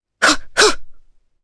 Requina-Vox_Attack1_jpc.wav